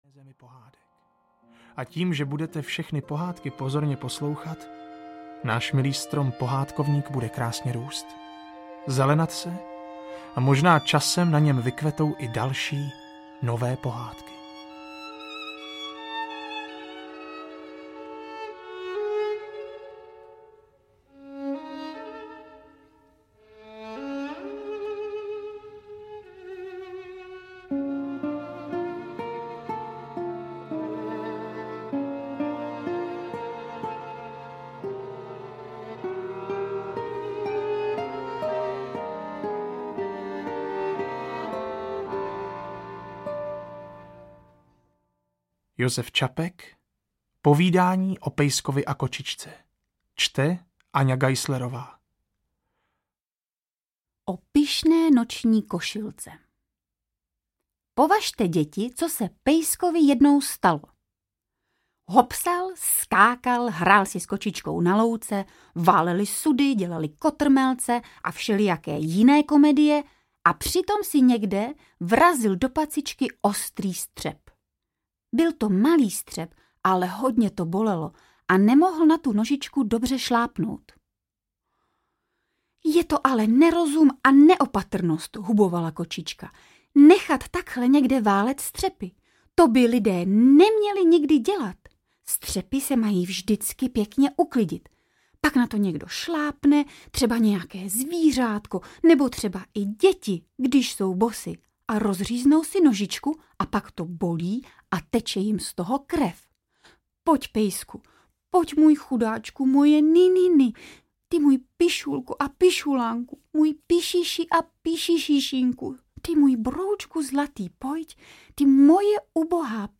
Pohádkovník aneb Herci čtou své oblíbené pohádky audiokniha
Audio knihaPohádkovník aneb Herci čtou své oblíbené pohádky
• InterpretAňa Geislerová, Barbora Poláková, Jakub Prachař, Patrik Děrgel, Václav Jílek, Jiří Suchý z Tábora